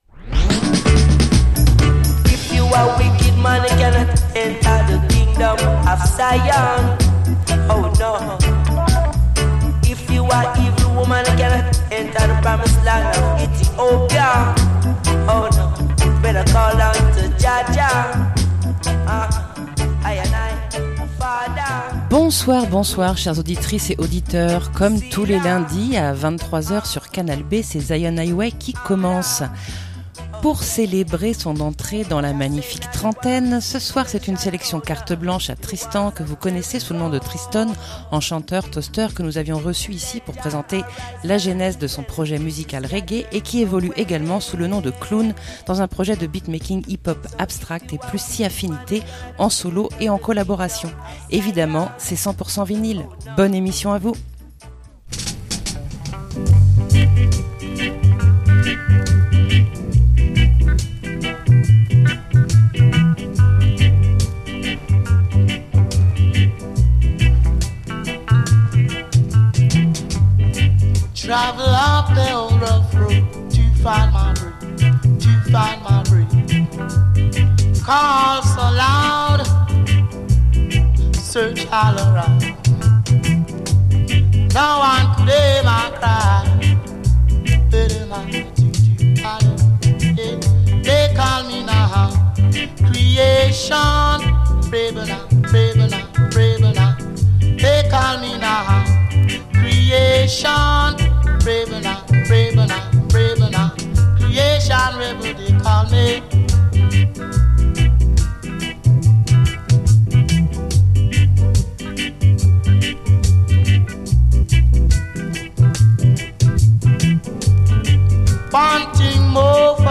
Evidemment c’est tout en vinyl!